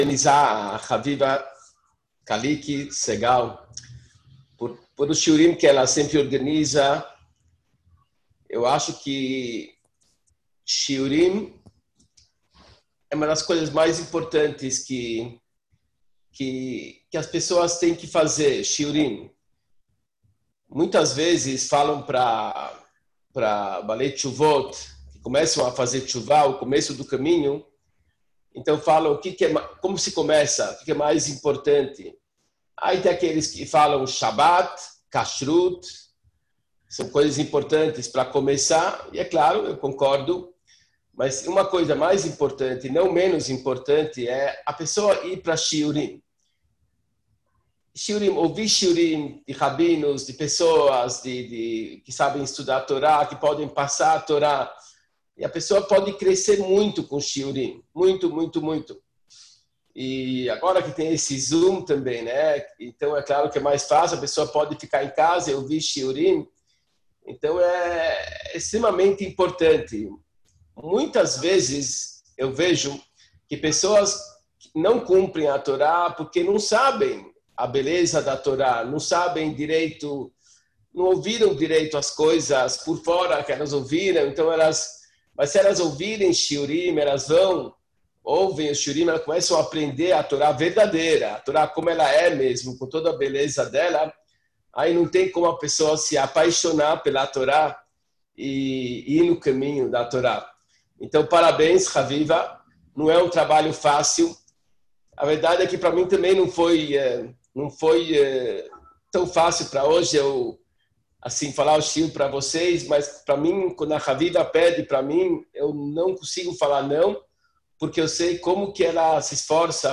Tisha BeAv – Emuná – Shiur ministrado para publico feminino